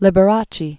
(lĭbə-rächē), (Wladziu) 1919-1987.